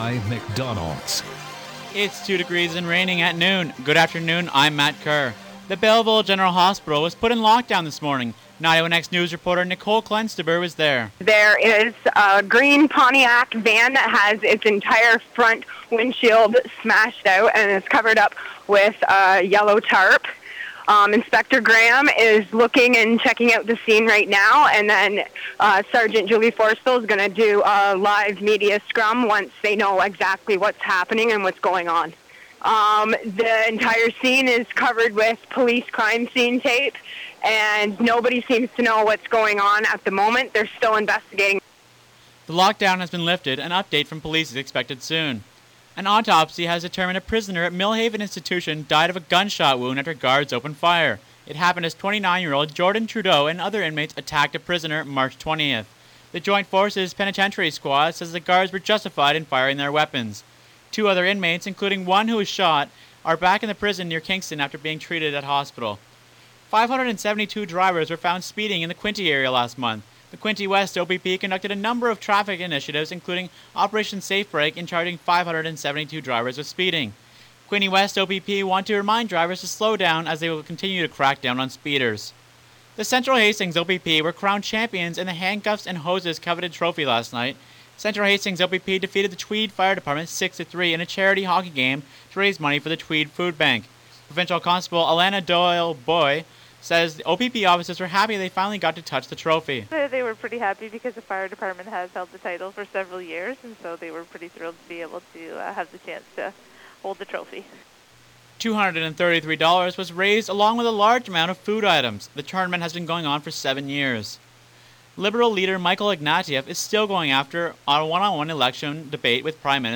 91x-news-noon-april-1.mp3